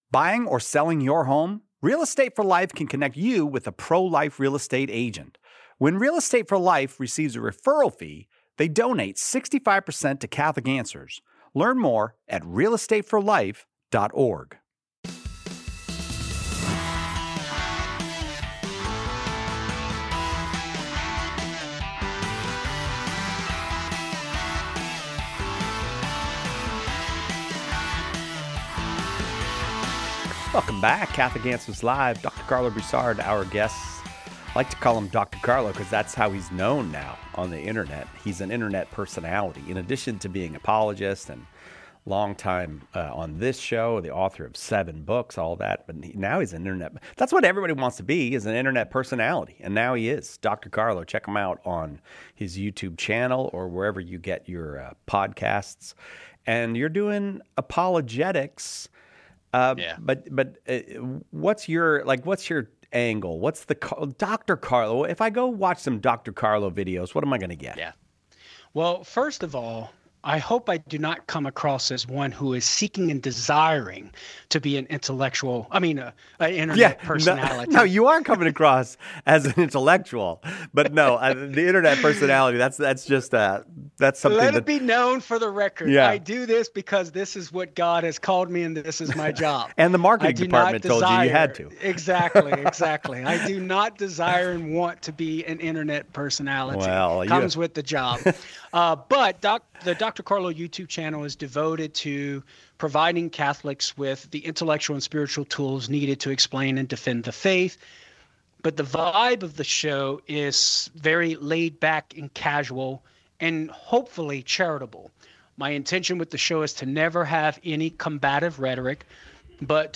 In this episode of Catholic Answers Live , Catholic Answers apologists explore this foundational question before diving into others on the core of Christian belief. They explain the evidence for the resurrection, whether Pascal’s Wager could justify belief in the Eucharist, and if our prayers can override God’s will.